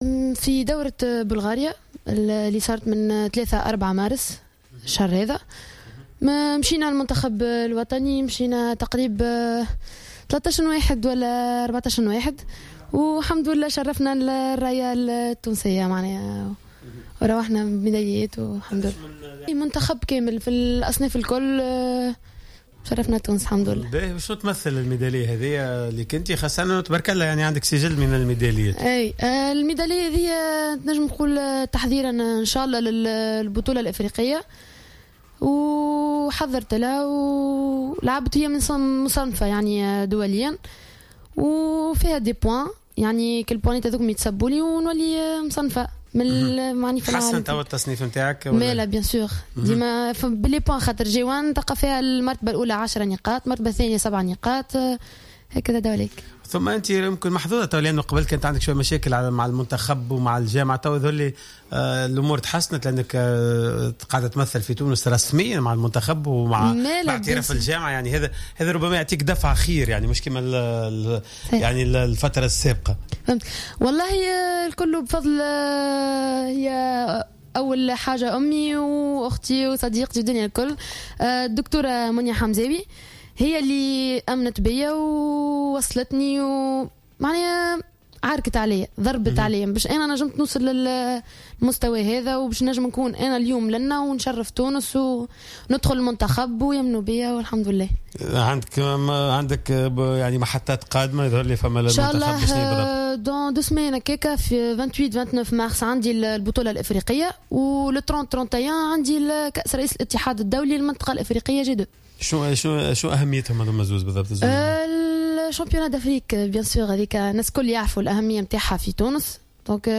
ضيفة على حصة "راديو سبور"